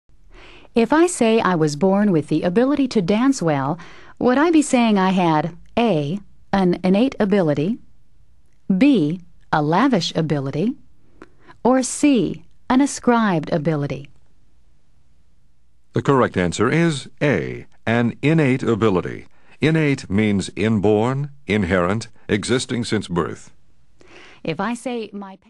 Learn 1000s of new words, each pronounced, spelled, defined, and used in a sentence.